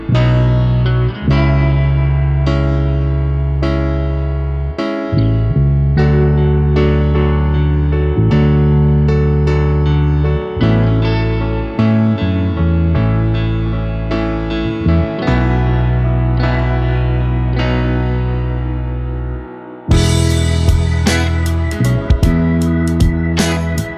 One Semitone Down Pop (2010s) 5:47 Buy £1.50